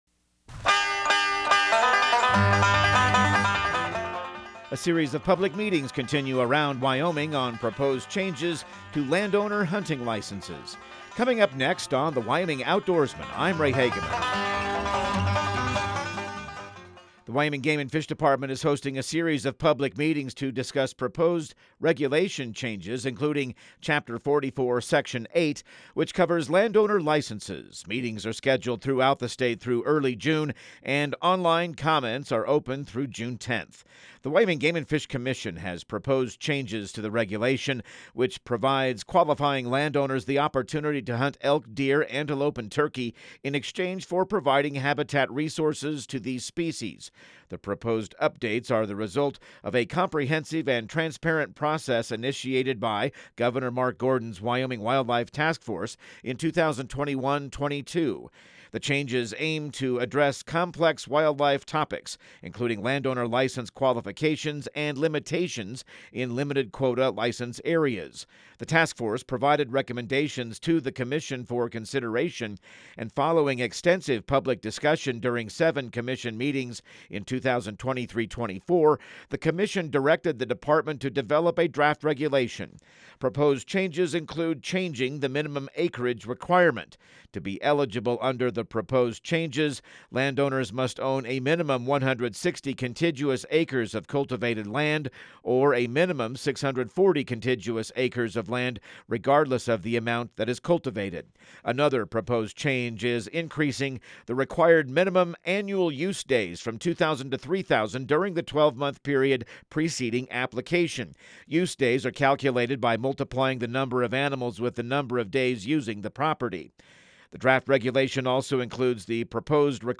Radio news | Week of May 26